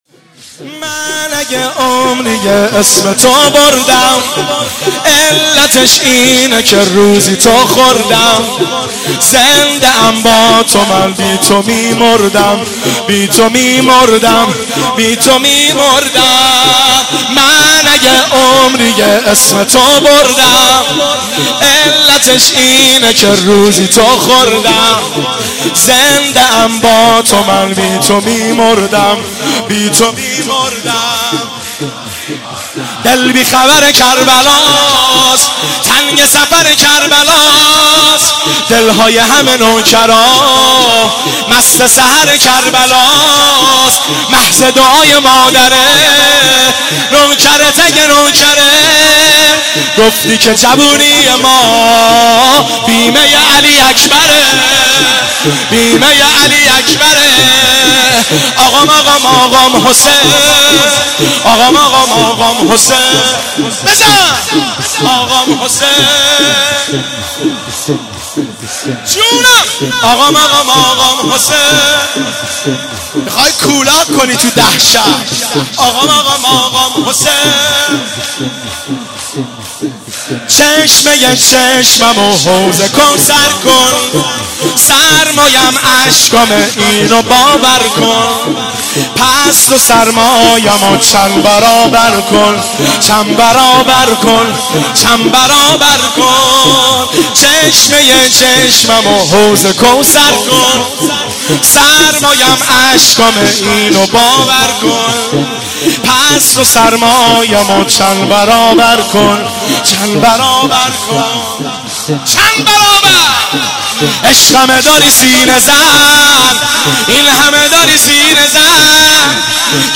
• دانلود نوحه و مداحی